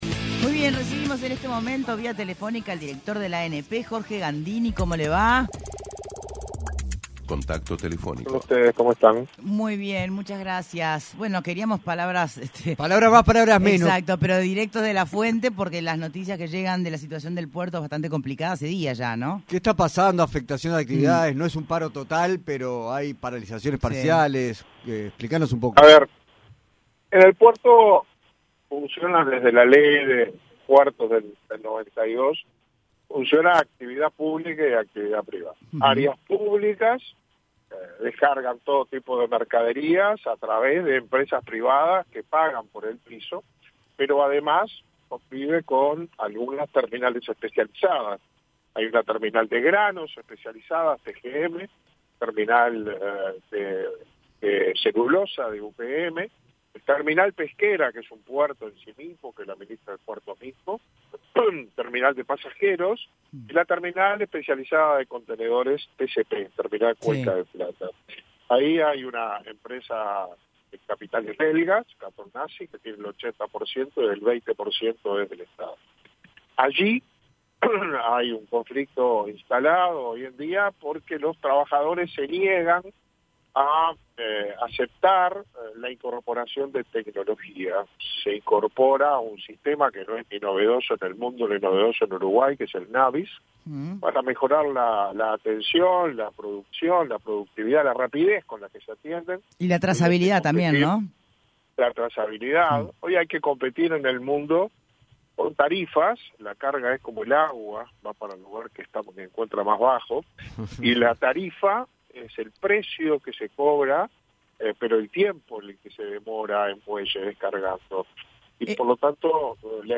El director en la Administración Nacional de Puerto por el Partido Nacional, Jorge Gandini, dijo en entrevista con Punto de Encuentro que las medidas del sindicato de Terminal Cuenca del Plata, llevaron a cuatro buques de carga a seguir de largo, al no tener la seguridad de poder operar en el Puerto de Montevideo.